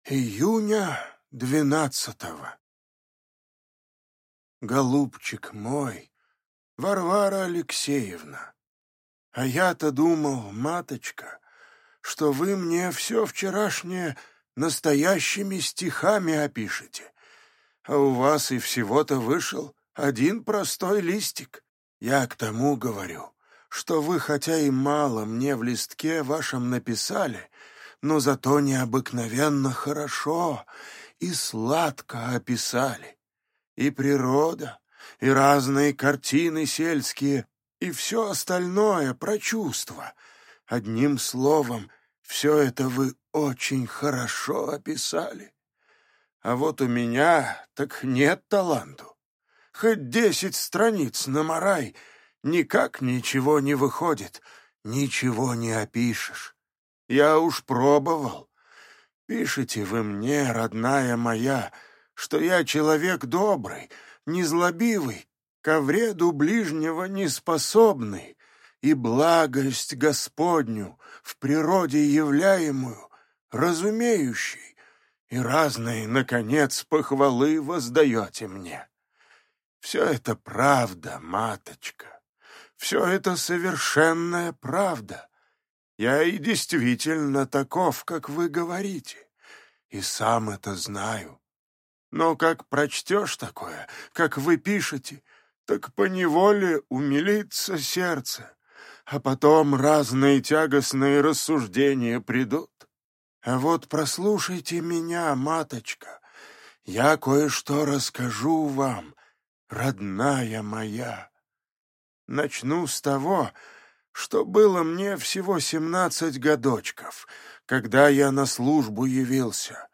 Аудиокнига Бедные люди. Двойник | Библиотека аудиокниг